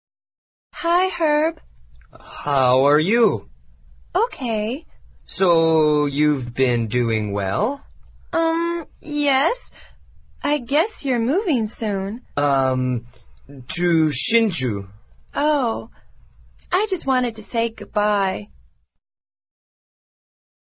SCENE② C 欢送会上 柔丝跟赫伯说话